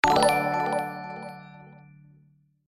positive-win-game-sound-4_ljfs7ox_p3AKDy2.mp3